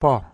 고하강성조 (High Falling)여성pojpox
몽어 poj 발음